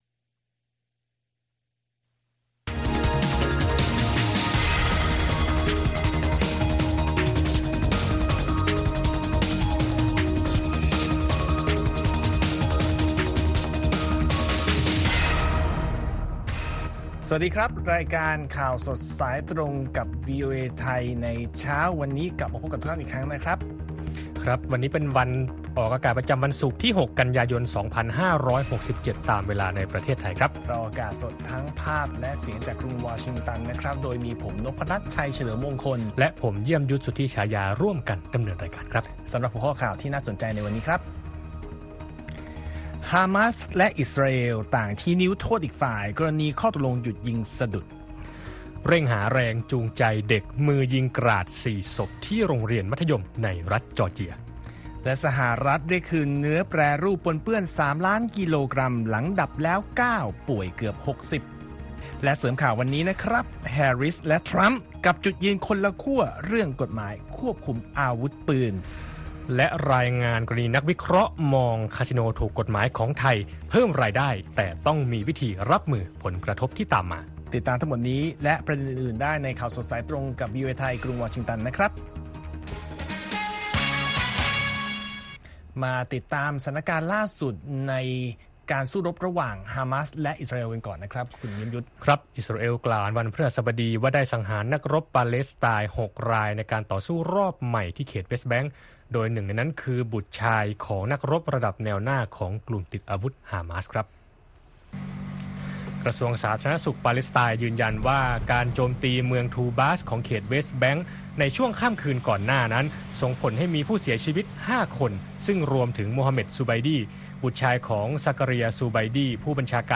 ข่าวสดสายตรงจากวีโอเอ ไทย ประจำวันศุกร์ที่ 6 กันยายน 2567